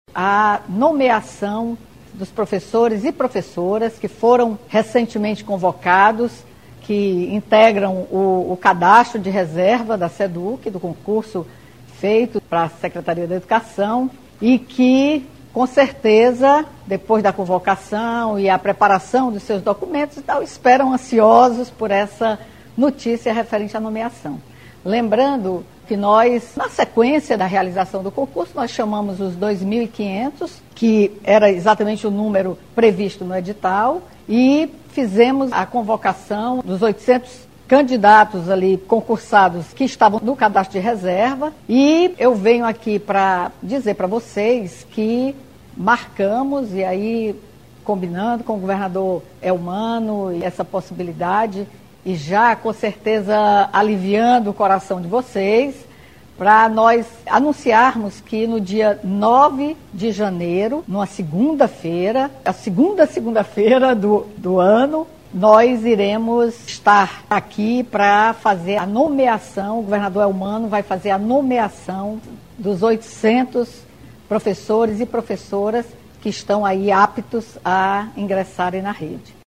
O ano letivo de 2023 vai começar com a nomeação de 800 professores efetivos na rede pública estadual. A novidade, agendada para 9 janeiro, foi informada pela governadora Izolda Cela, junto com o governador eleito Elmano de Freitas, em transmissão ao vivo pelas redes sociais na manhã de hoje.